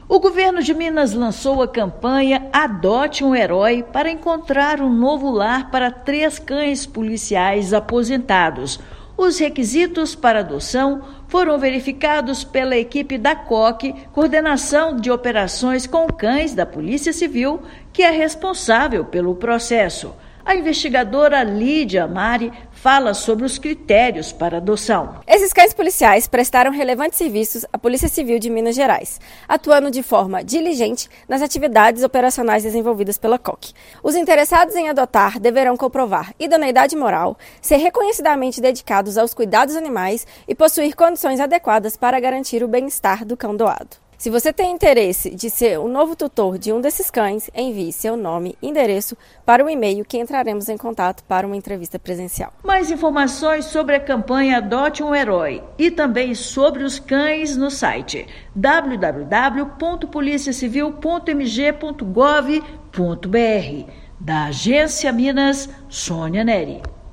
Zoe, Bud e Ivy buscam novo lar para curtir a aposentadoria e desfrutar do merecido descanso. Ouça matéria de rádio.